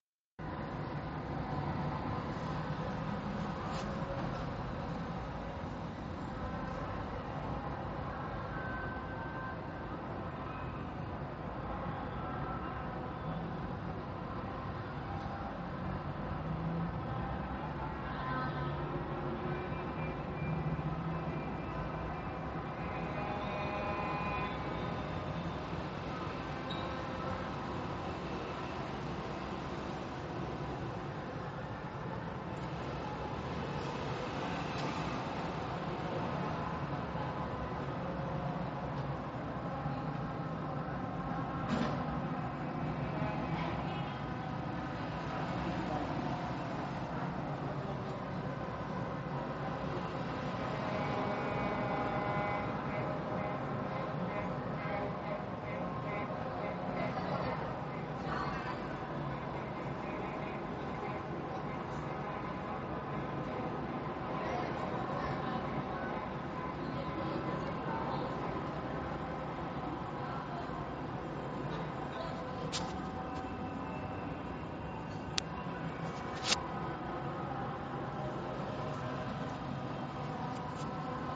بازی استقلال خوزستان و تراکتور که شروع شد، صدای شیپورها اوج گرفت.
باران جایش را به تگرگ داد و صدای رقص و آواز ترکی ادامه داشت.
اما صداها به قدری واضح بود که انگار کسی دم گوشم شیپور می‌زد.